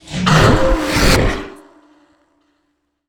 dark_magic_conjure_blast_4.wav